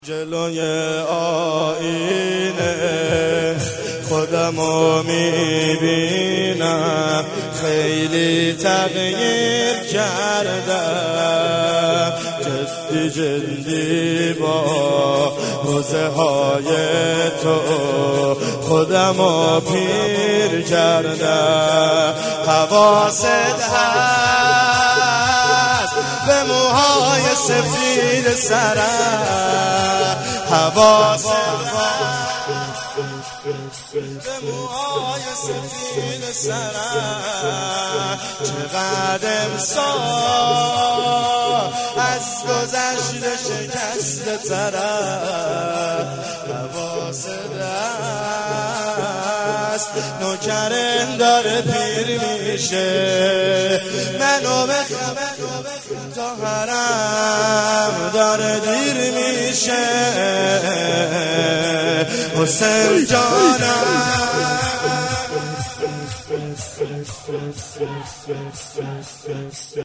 جلسه مذهبی بنت الحسین شهادت امام حسن مجتبی(ع)